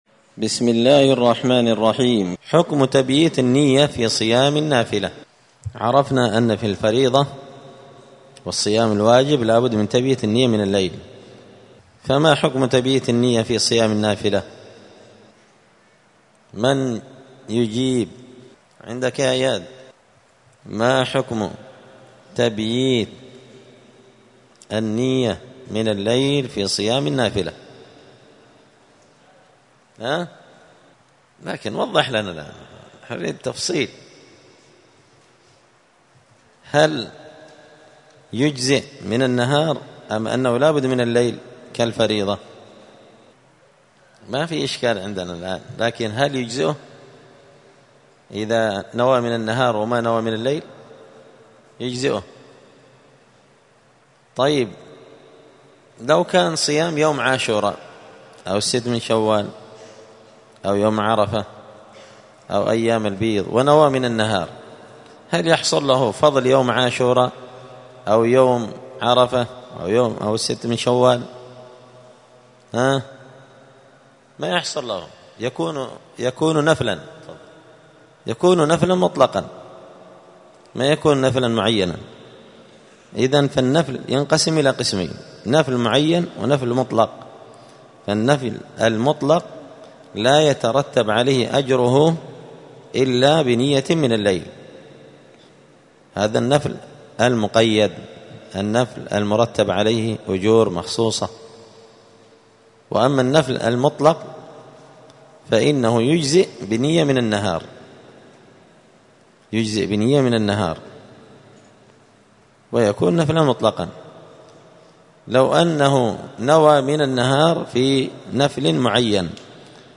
دار الحديث السلفية بمسجد الفرقان بقشن المهرة اليمن
*الدرس السابع (7) {حكم تبييت النية من الليل في صيام النافلة…}*